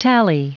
Prononciation du mot tally en anglais (fichier audio)
Prononciation du mot : tally